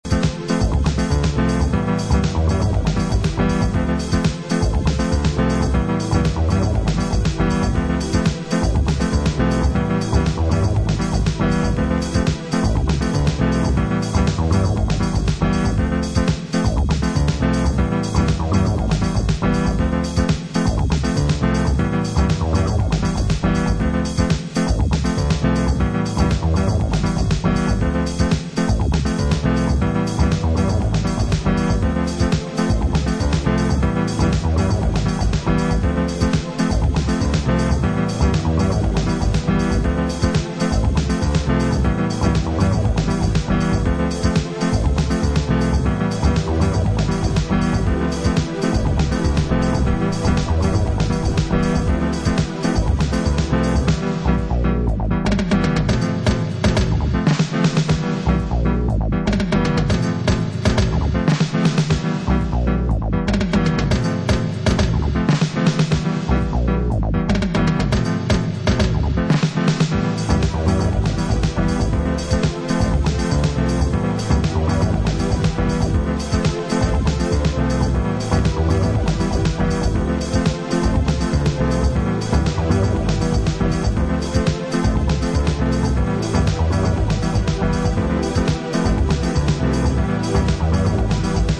Classic chicago acid house reissue